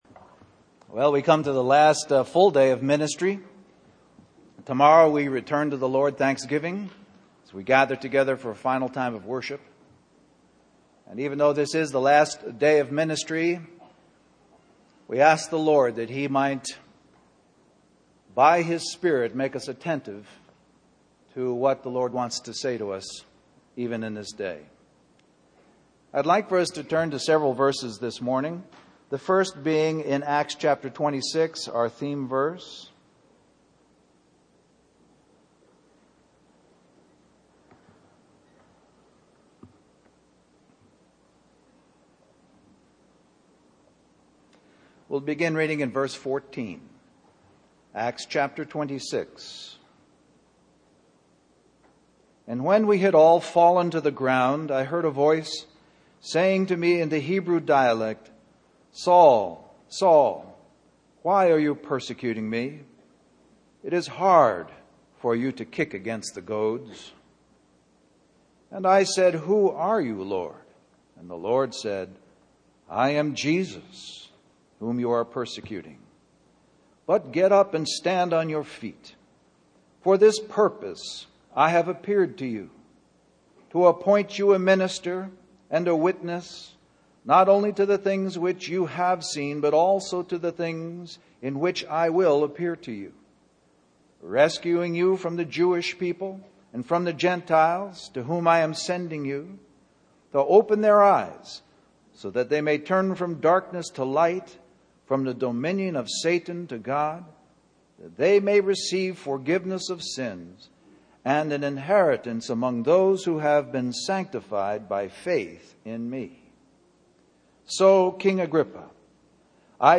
A collection of Christ focused messages published by the Christian Testimony Ministry in Richmond, VA.
2007 Christian Family Conference